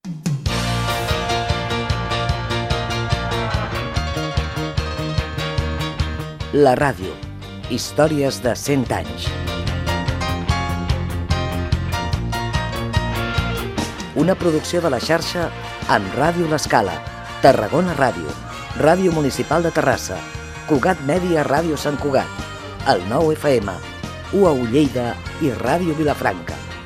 Careta de sortida del programa amb els noms de les emissores que han participat al programa